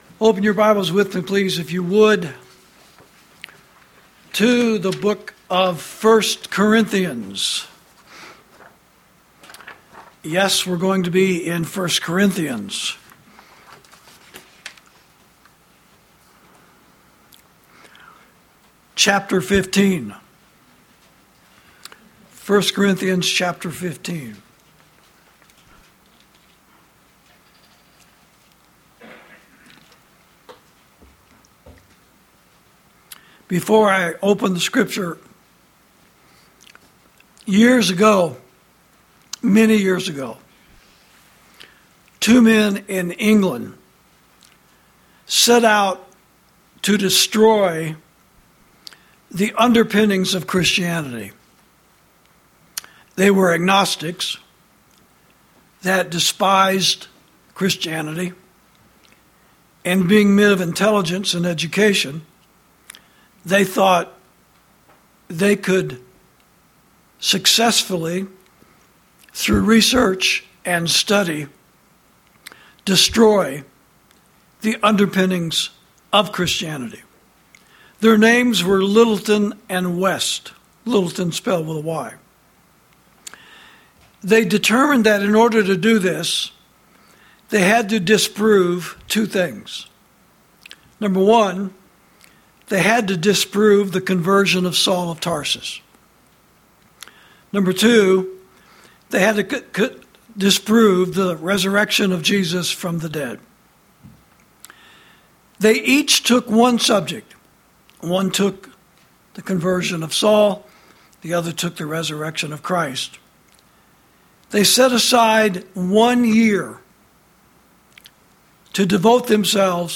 Sermons > Christ Is Risen From The Dead